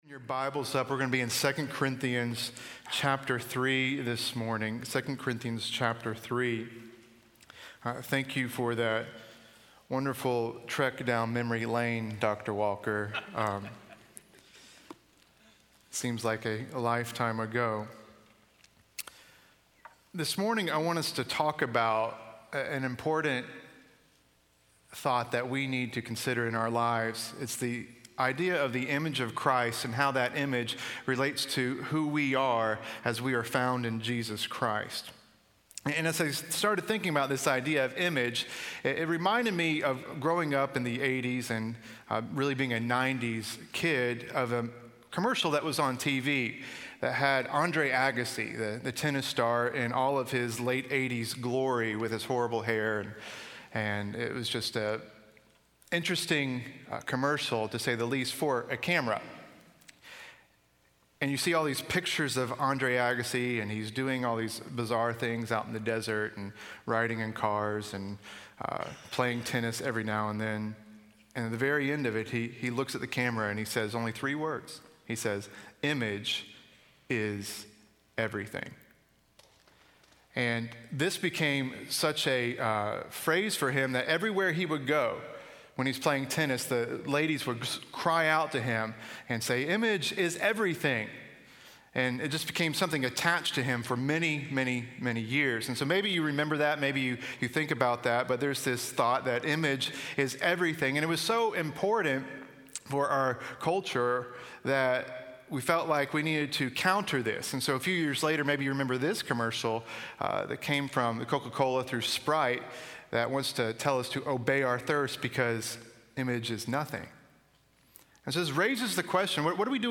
speaking on II Corinthians 3 in SWBTS Chapel